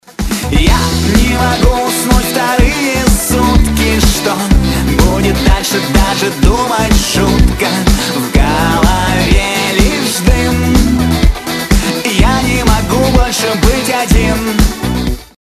• Качество: 256, Stereo
поп
dance
поп-рок